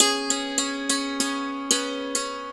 SANTOOR2  -R.wav